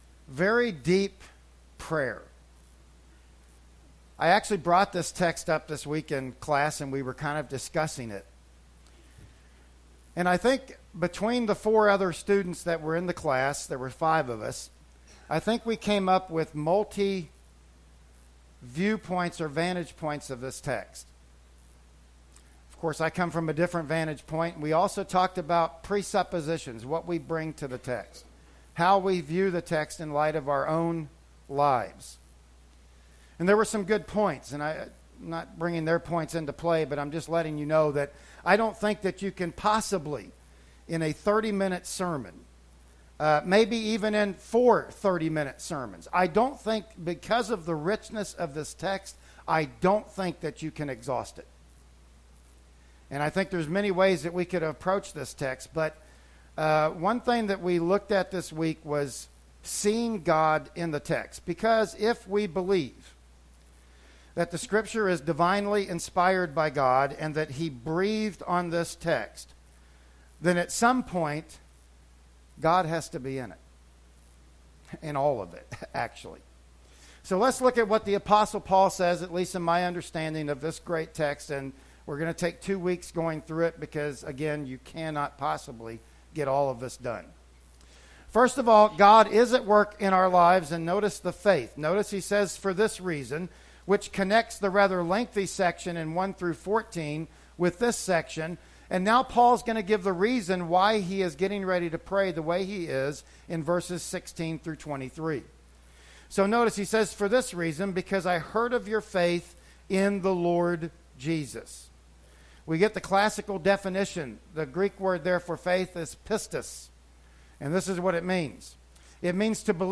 Service Type: Sunday Morning Worship Service